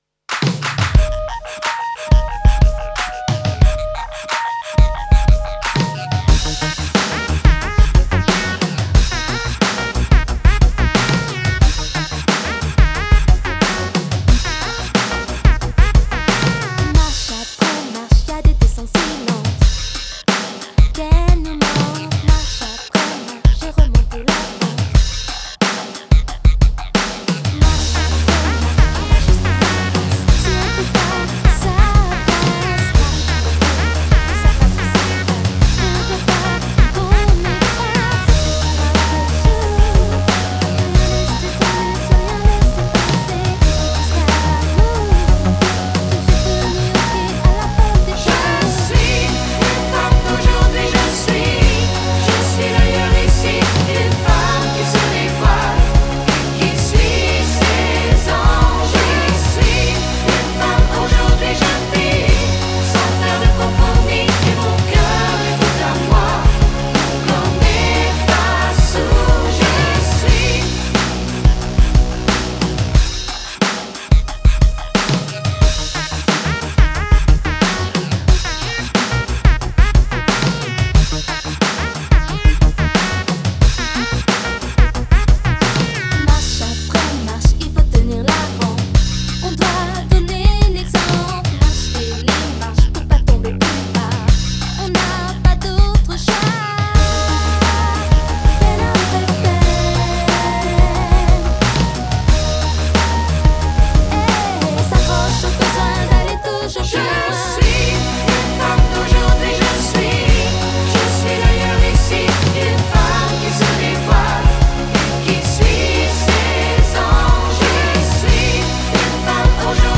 femme-d-aujourd-hui-instrumental.mp3